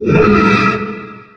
3098b9f051 Divergent / mods / Soundscape Overhaul / gamedata / sounds / monsters / poltergeist / idle_1.ogg 26 KiB (Stored with Git LFS) Raw History